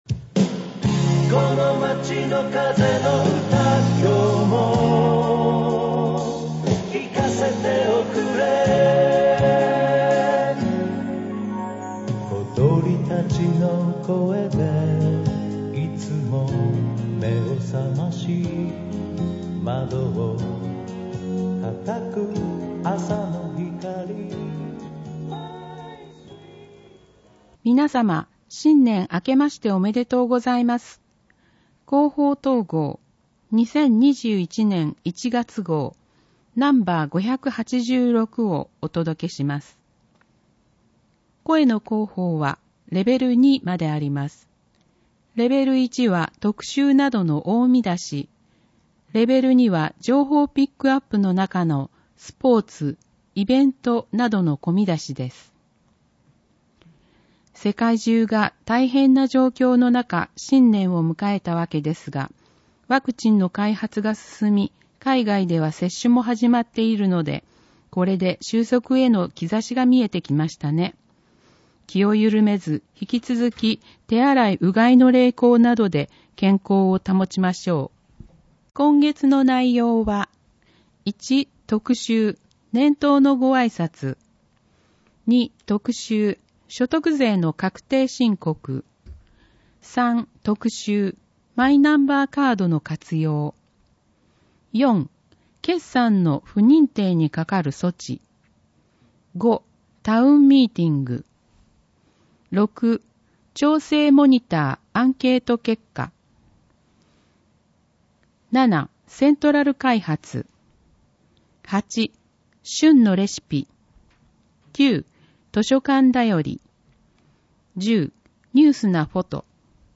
広報とうごう音訳版（2021年1月号）